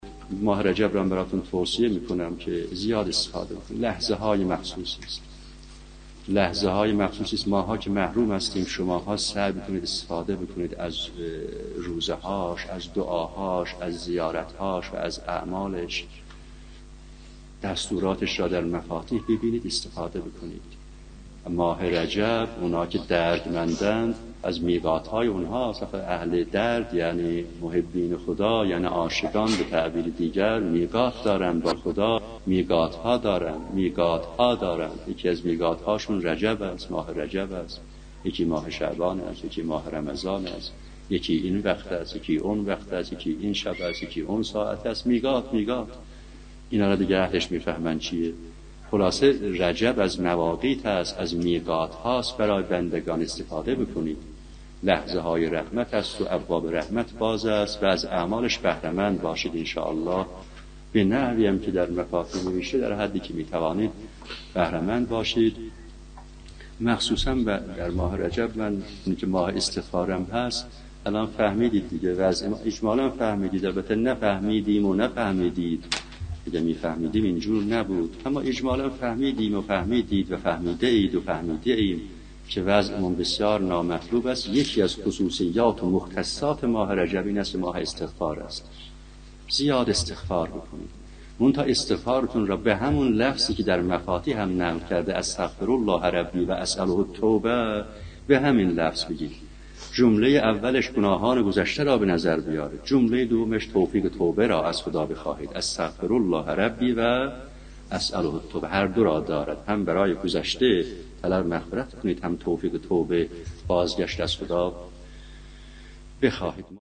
در یکی از سخنرانی‌هایشان درباره اهمیت لحظات ماه رجب می‌گوید